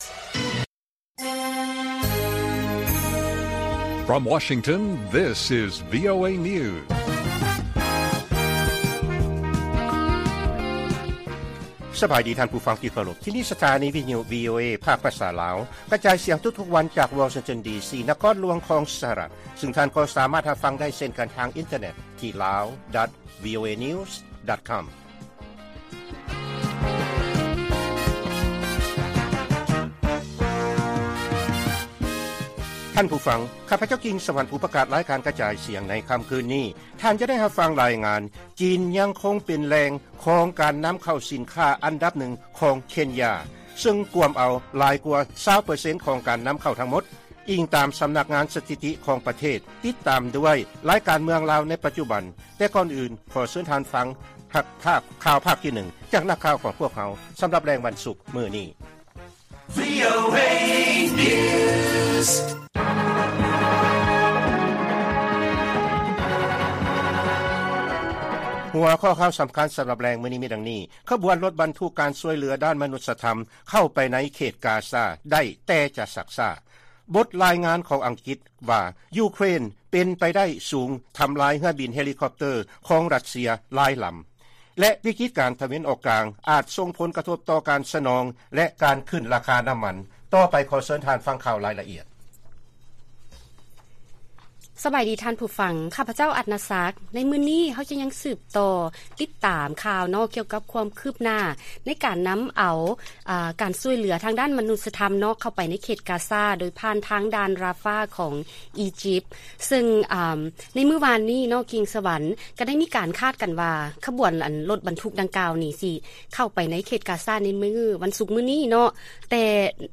ລາຍການກະຈາຍສຽງຂອງວີໂອເອ ລາວ: ຂະບວນລົດບັນທຸກການຊ່ອຍເຫຼືອດ້ານມະນຸດສະທຳ ເຂົ້າໄປໃນເຂດກາຊາ ໄດ້ ແຕ່ຈະຊັກຊ້າ